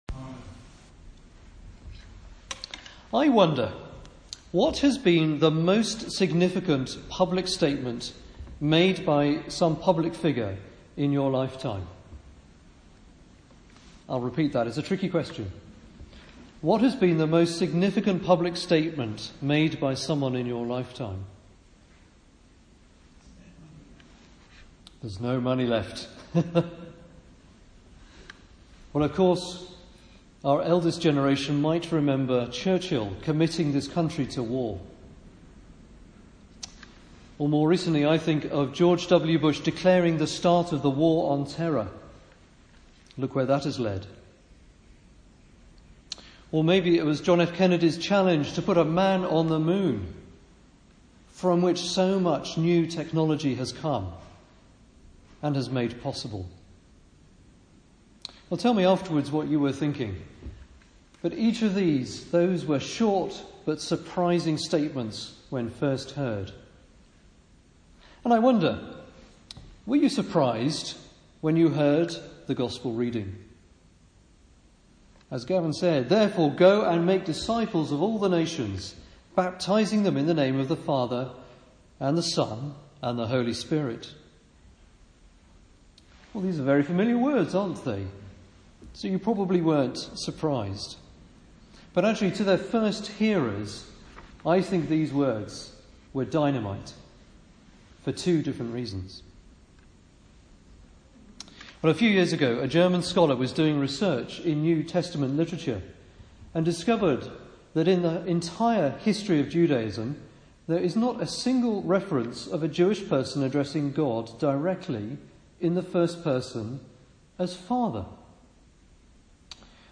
A sermon on Matthew 28.16-20 preached at Southrop church, Trinity Sunday, 2017.